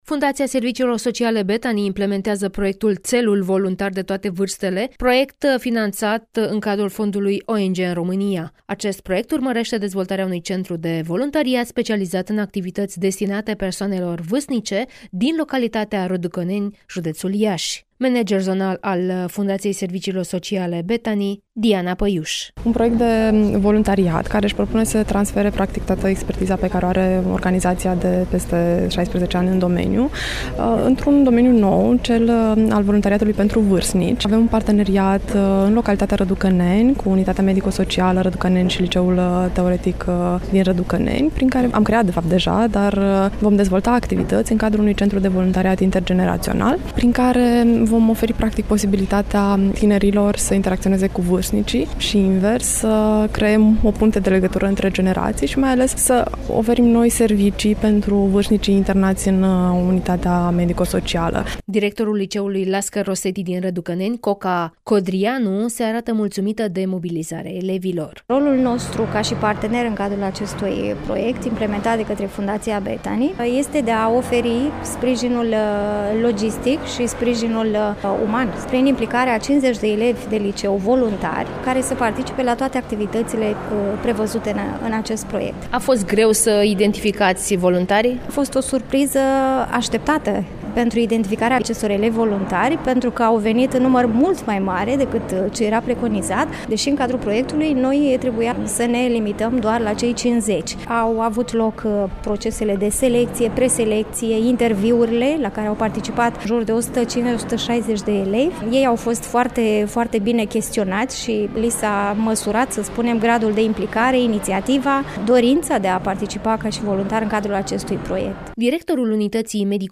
(REPORTAJ) Centru de voluntariat specializat în activități destinate persoanelor vârstnice din Răducaneni